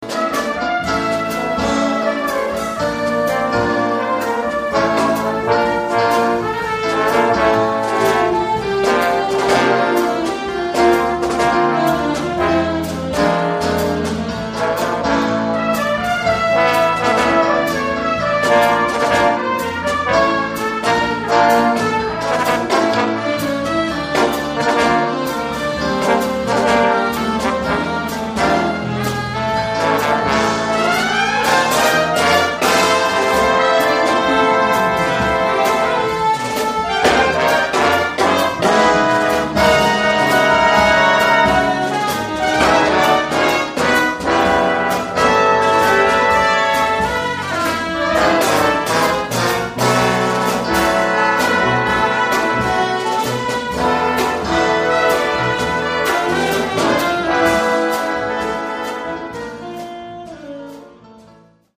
BIG BAND ARRANGEMENTS FROM
Spanish piece with solos for Guitar, Alto and Tenor. Line-up: 4 trumpets, 4 trombones, 2 Altos, Tenor, Tenor on Soprano, 2 Flutes, Piano, Guitar, Bass, Drums, Leadsheet.